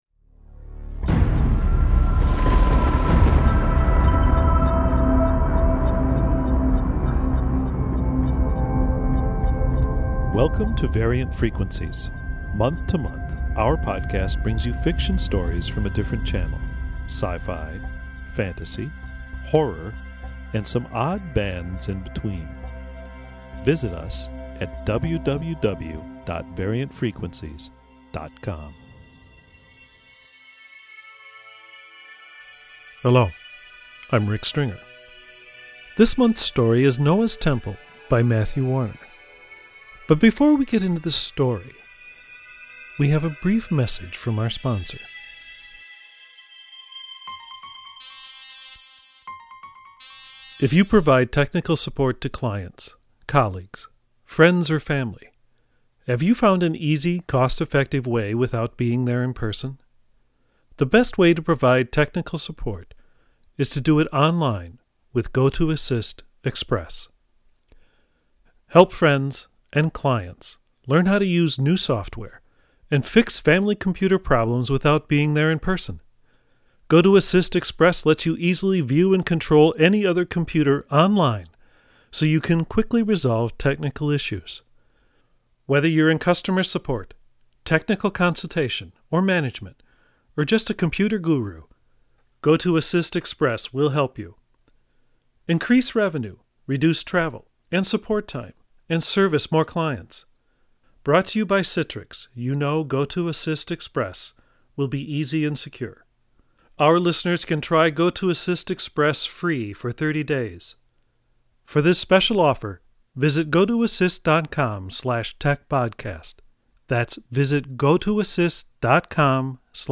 Audio Fiction